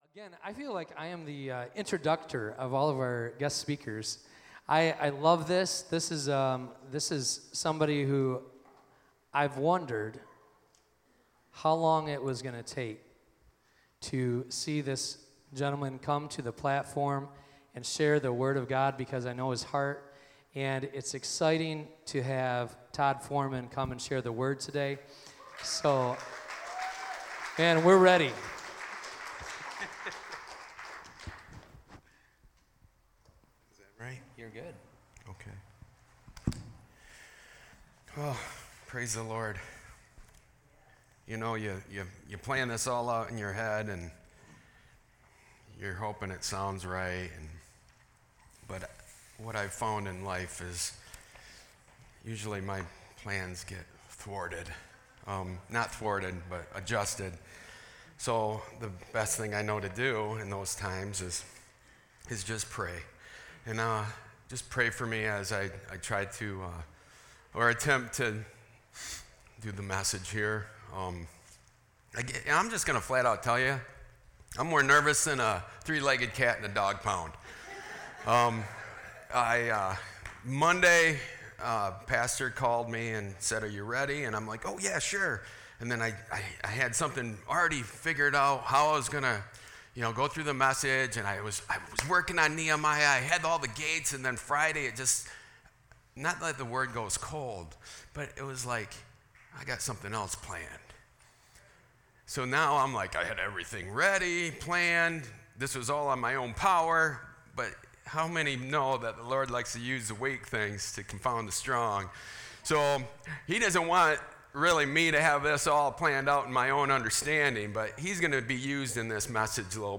Sermon-8-25-24.mp3